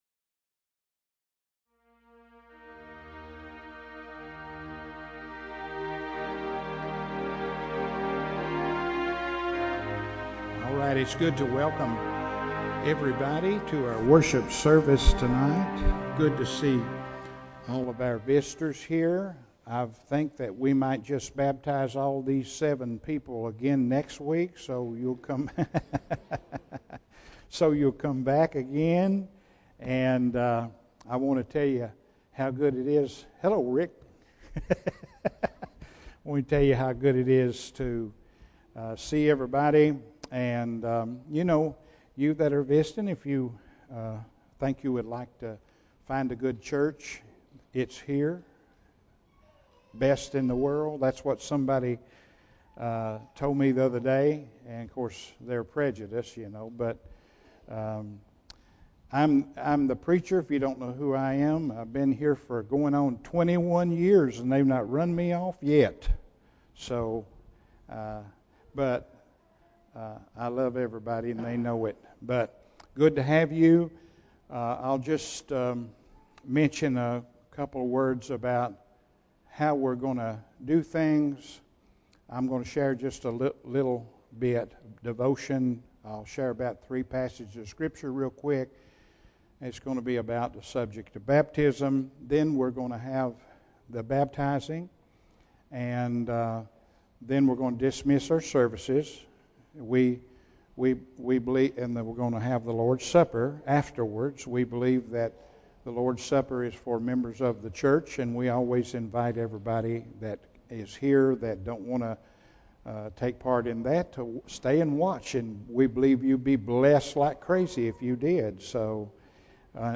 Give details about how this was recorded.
Passage: Luke 10:38-42 Service Type: Morning Service